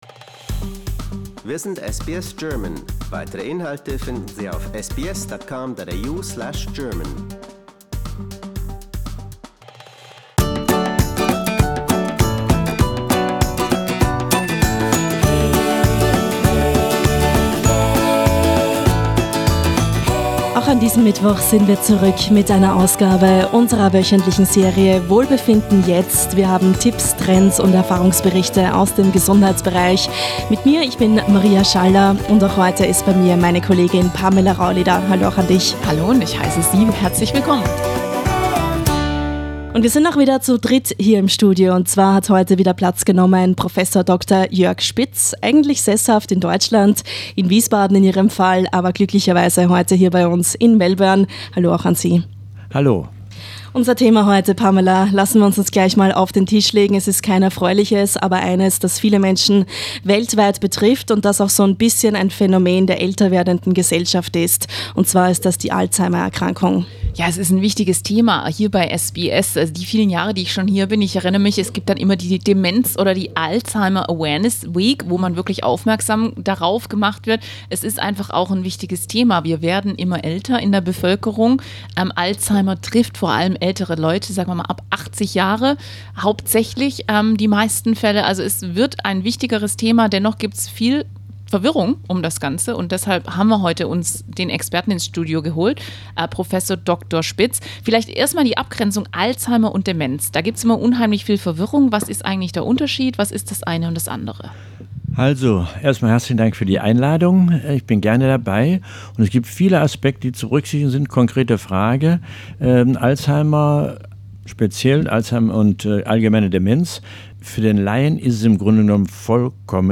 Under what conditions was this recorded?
Today: A studio interview with German health expert